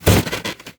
anonDeskSlam.ogg